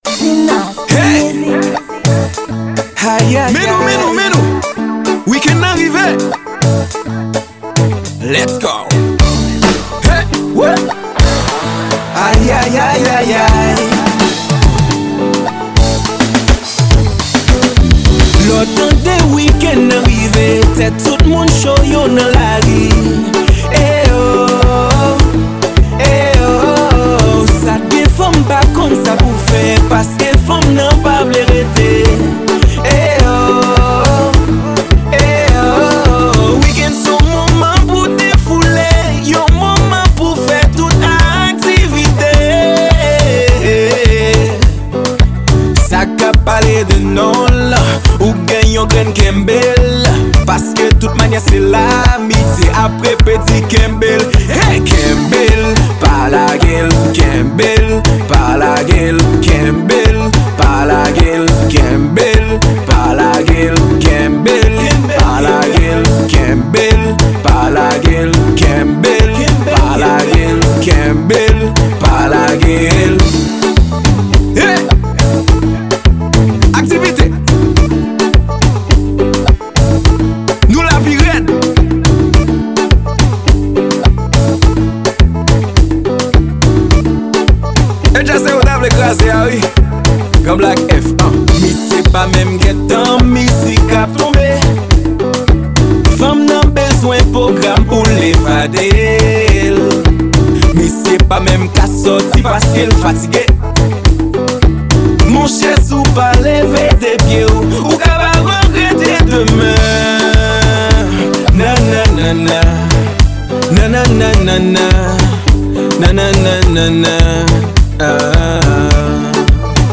Genre: Dance Music.